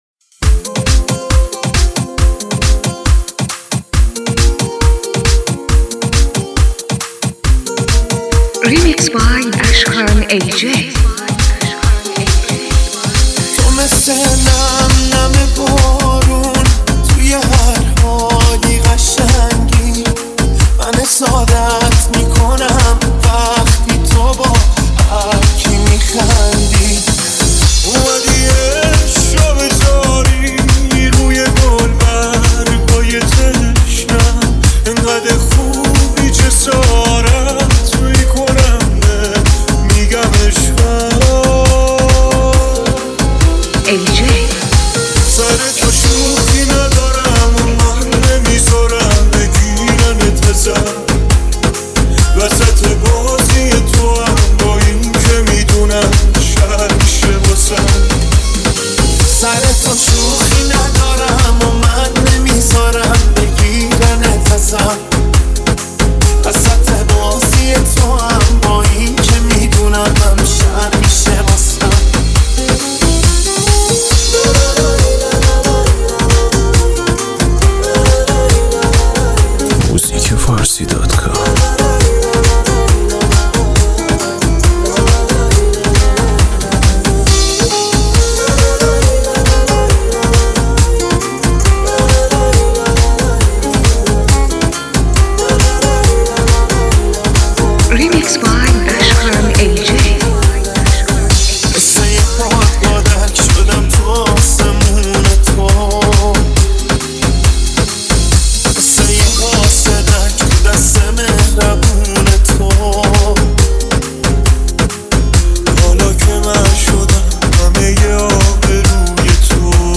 سیستمی بیس دار تند
ریمیکس سیستمی تند بیس دار